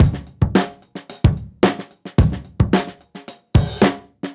Index of /musicradar/dusty-funk-samples/Beats/110bpm
DF_BeatD_110-01.wav